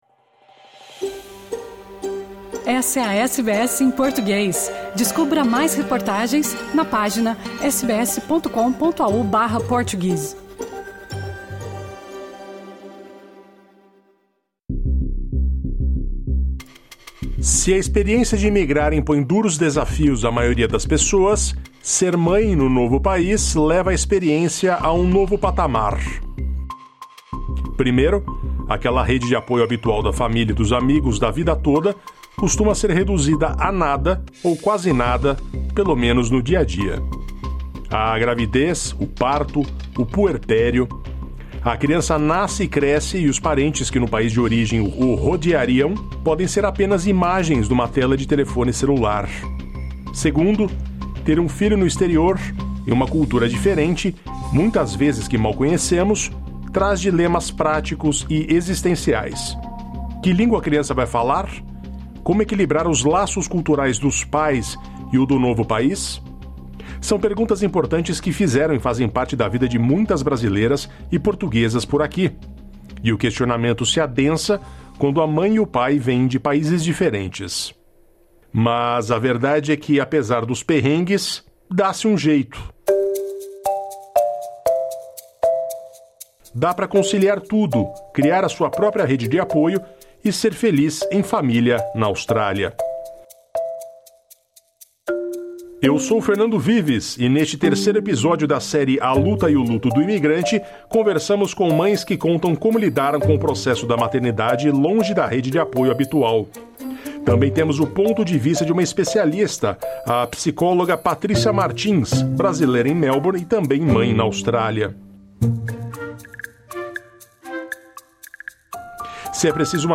A gravidez, o parto e o puerpério acrescentam alguns graus de dificuldade na vida de imigrante, mas lidar com as questões da maternidade distante é plenamente viável. Nesta reportagem da série 'A Luta e o Luto do Imigrante', ouvimos duas brasileiras e uma portuguesa, mães em Down Under, e também uma psicóloga, sobre a experiência.